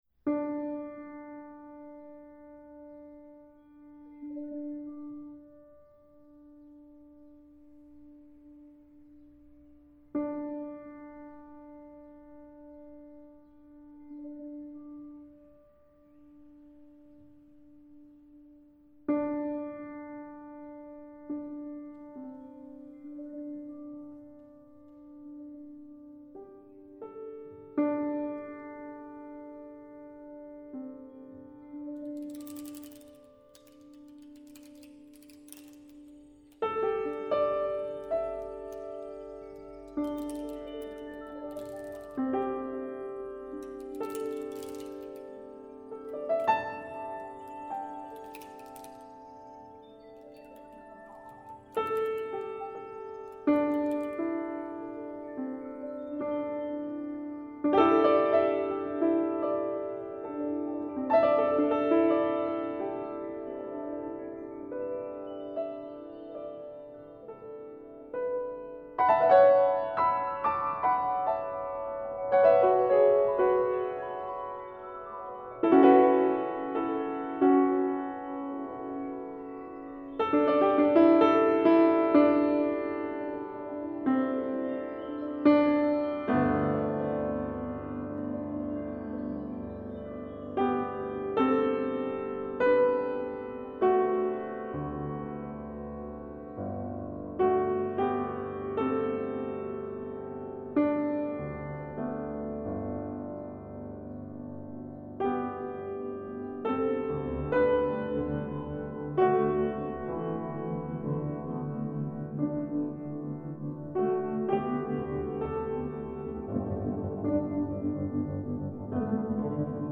Piano und Live-Elektronik